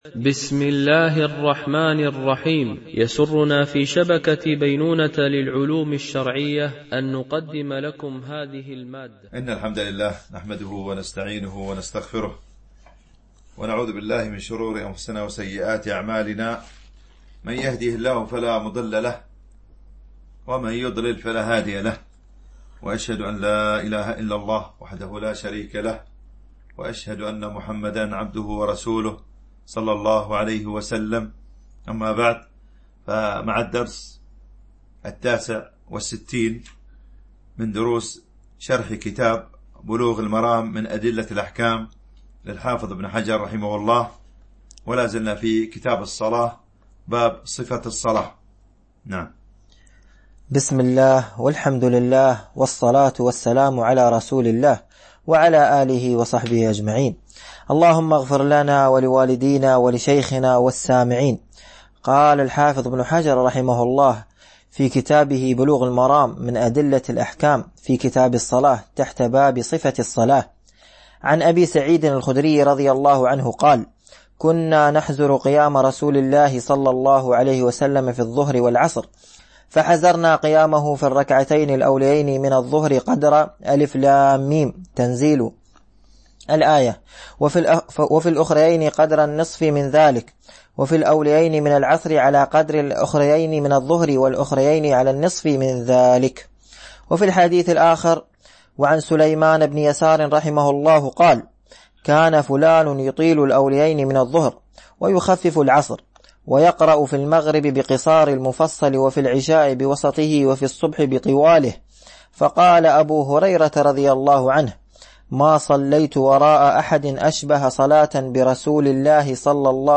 شرح بلوغ المرام من أدلة الأحكام - الدرس 69 ( كتاب الصلاة - باب صفة الصلاة , الحديث 287- 292 )